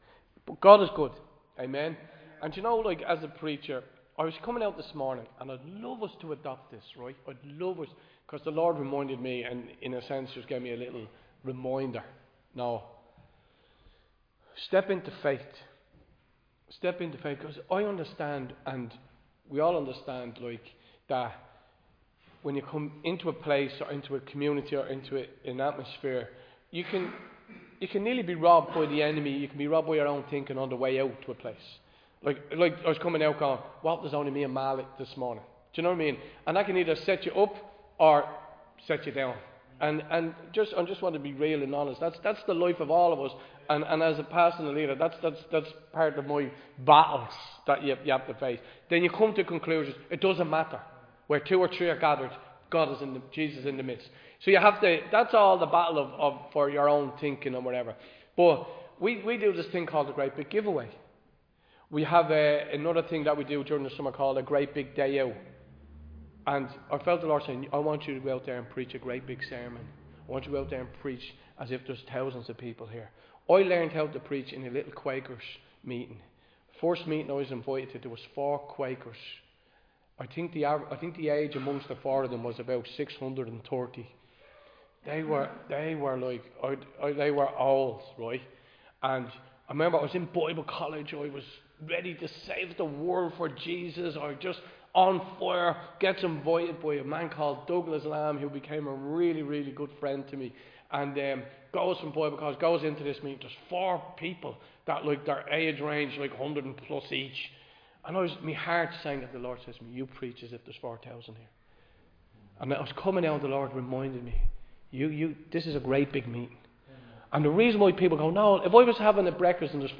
Recorded live in Liberty Church on 30 November 2025